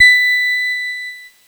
Cheese Note 29-B4.wav